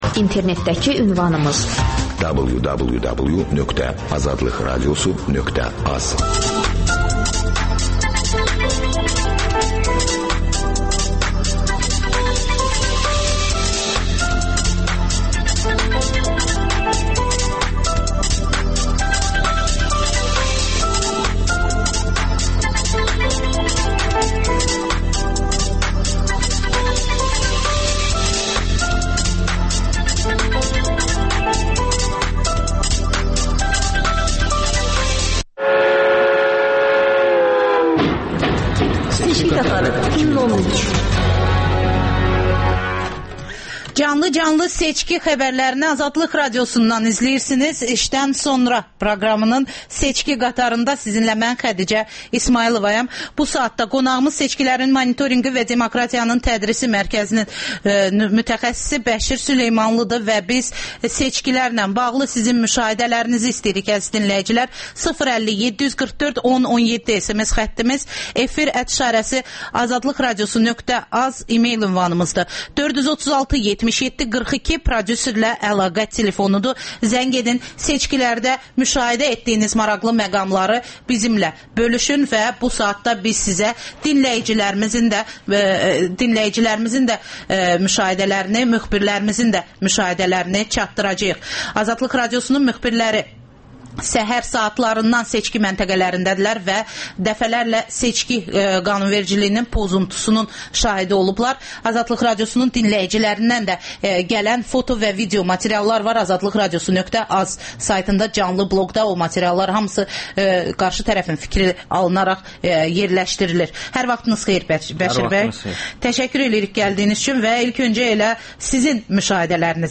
AzadlıqRadiosunun müxbirləri məntəqə-məntəqə dolaşıb səsvermənin gedişini xəbərləyirlər.